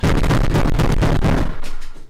desk-slam